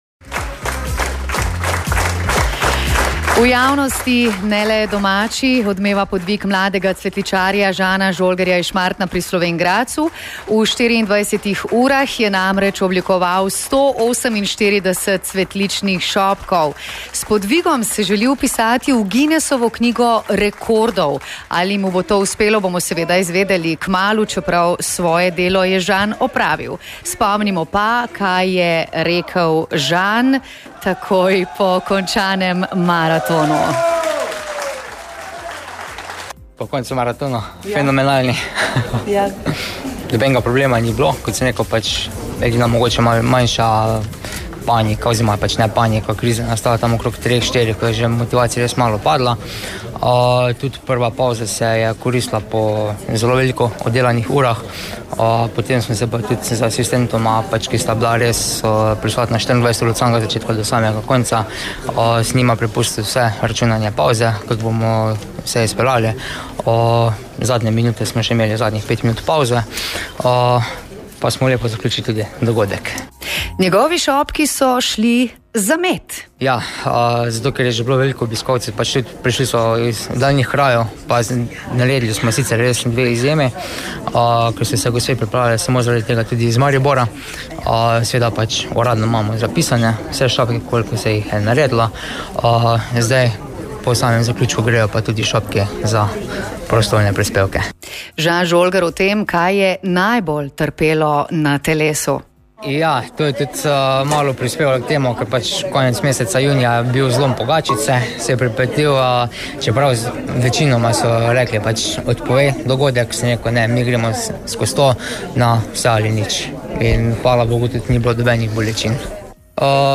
Pred mirkofon smo ga povabili takoj po 24 urah dela ...